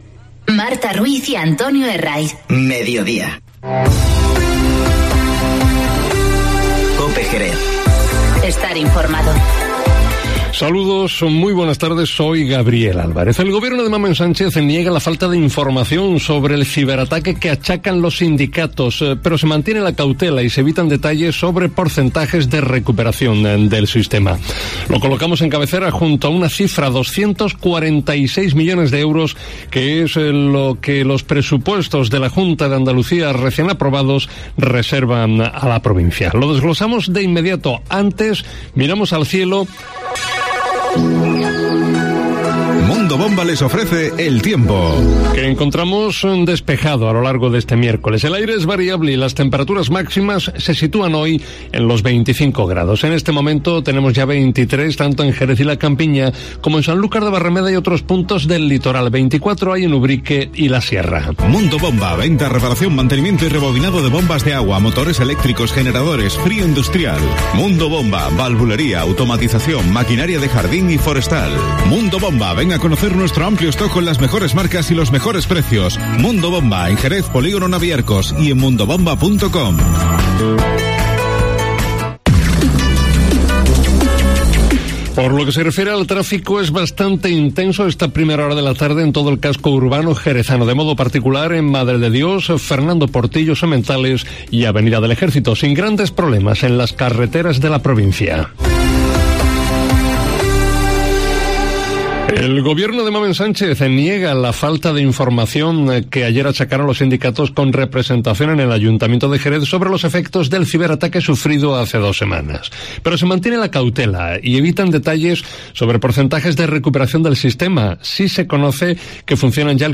Informativo Mediodía COPE en Jerez 16-10-19